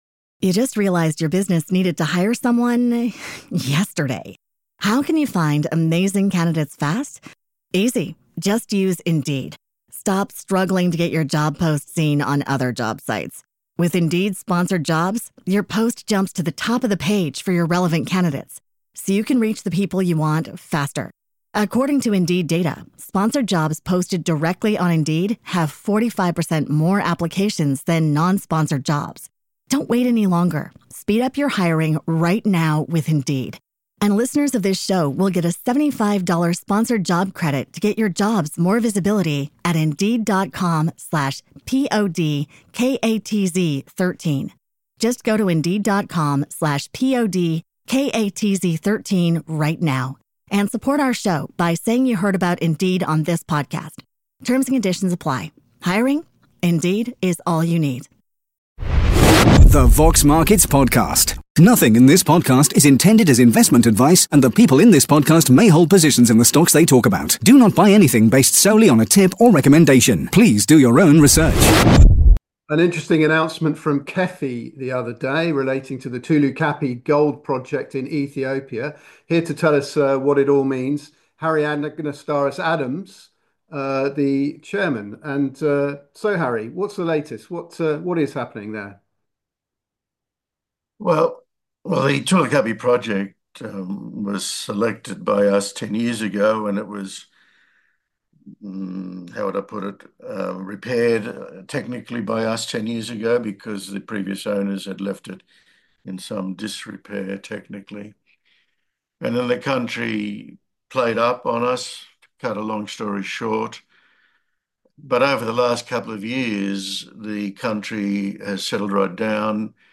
KEFI Gold and Copper Interview